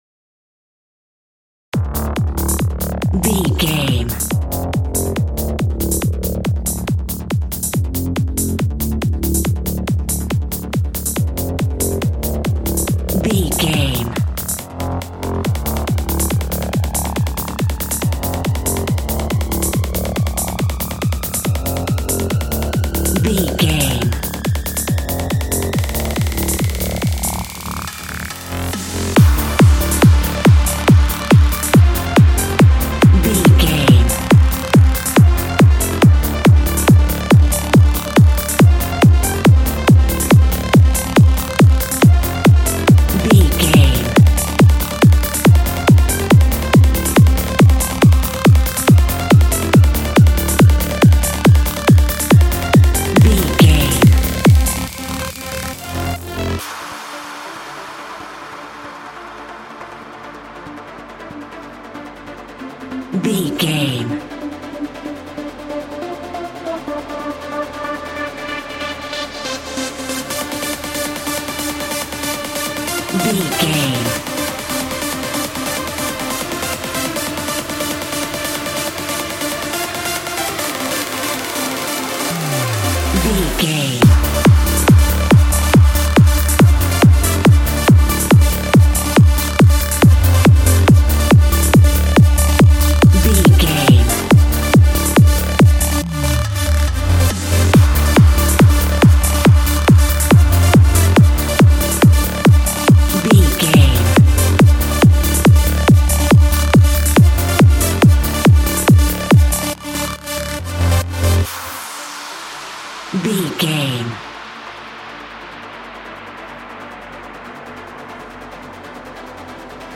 Epic / Action
Fast paced
Aeolian/Minor
aggressive
powerful
dark
groovy
uplifting
driving
energetic
drums
synthesiser
drum machine
acid house
electronic
synth leads
synth bass